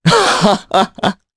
Crow-Vox_Happy3_jp.wav